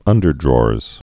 (ŭndər-drôrz)